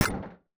UIClick_Menu Mallet Metal Hollow 03.wav